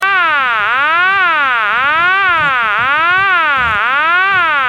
Generate The "No Such Number" Tone
This is one generated by a Chirp type tone.